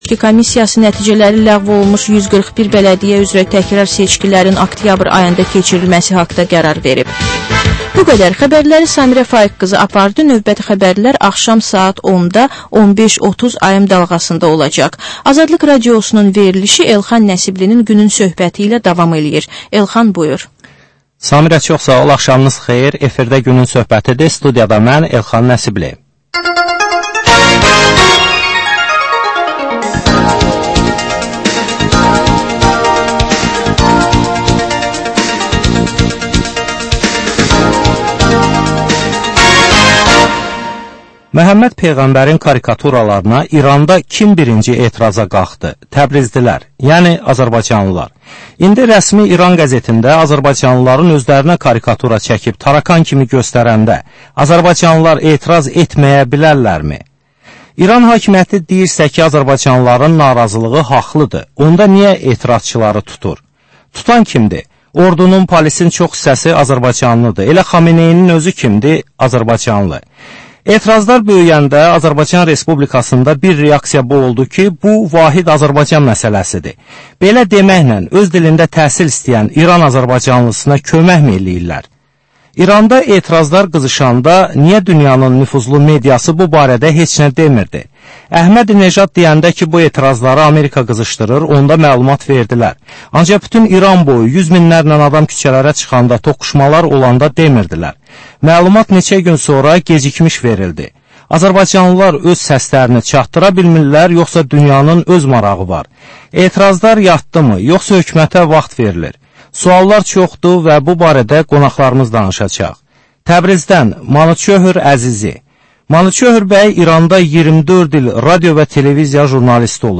Aktual mövzu barədə canlı dəyirmi masa söhbəti.